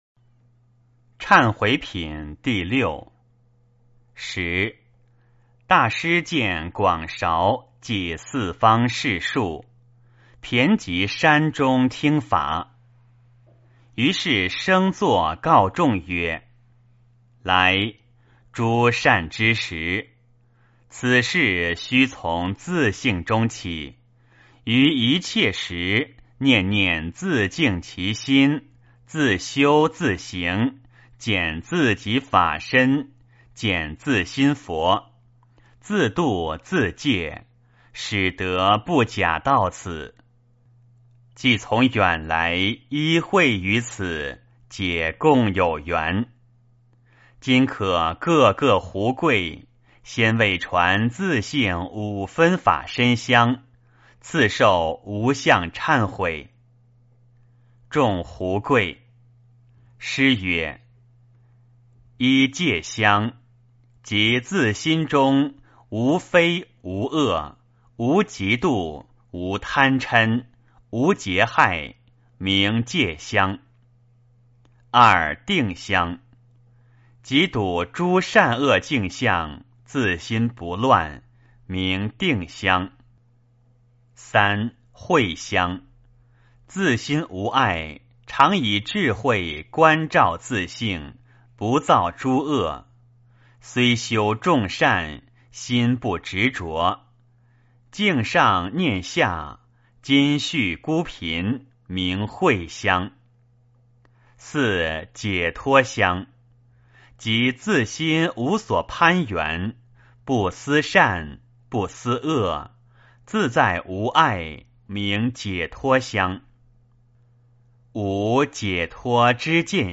坛经（忏悔品） 诵经 坛经（忏悔品）--未知 点我： 标签: 佛音 诵经 佛教音乐 返回列表 上一篇： 坛经（定慧品） 下一篇： 宝石经 相关文章 赞礼观音发愿偈--净土Pure Land 赞礼观音发愿偈--净土Pure Land...